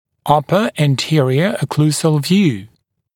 [‘ʌpə æn’tɪərɪə ə’kluːzəl vjuː] [-səl][‘апэ эн’тиэриэ э’клу:зэл вйу:] [-сэл]верхний переднеокклюзионный снимок, верхняя переднеокклюзионная проекция